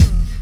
64BELLS-BD-L.wav